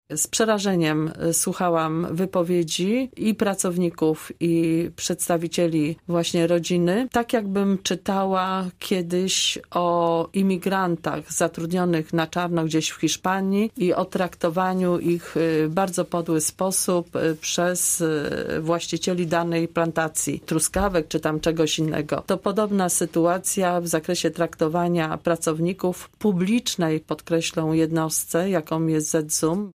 Radna B. Ronowicz o nadzwyczajnej sesji ws. ZZUM